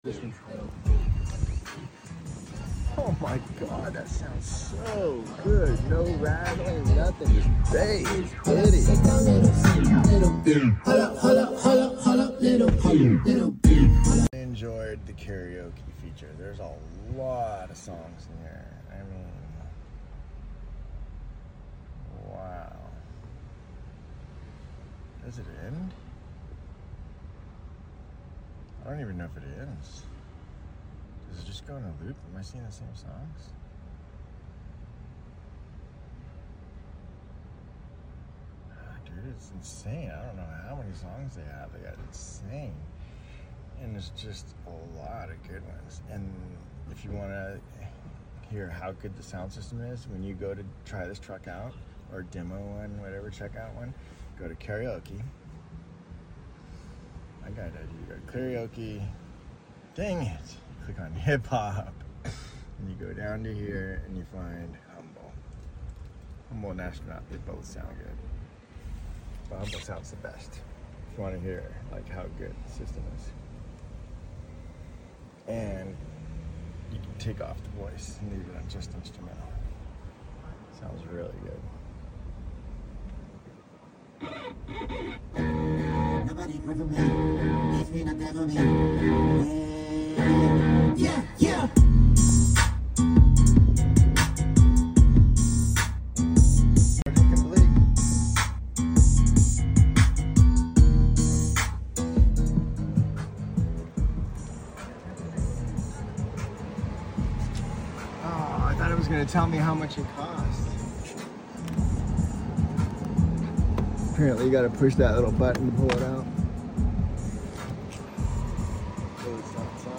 Sound system in the cyber truck.
Best sounding sound system.